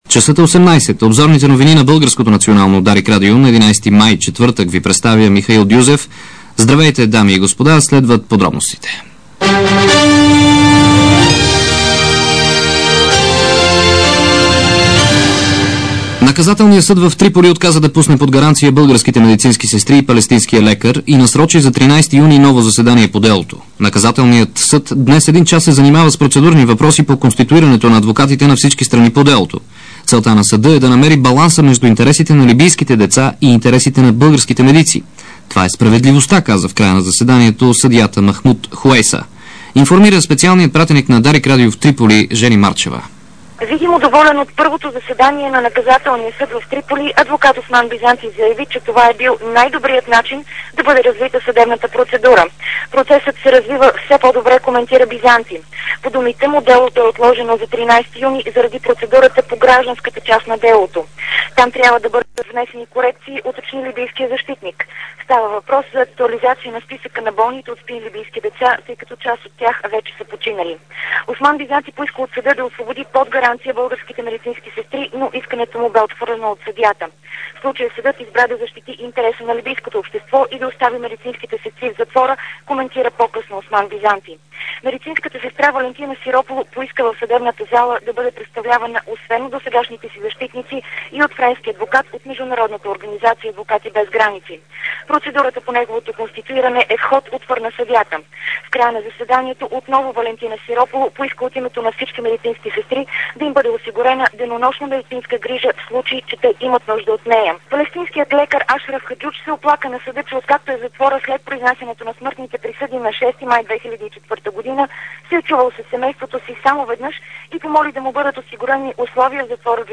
DarikNews audio: Обзорна информационна емисия – 11.05.2006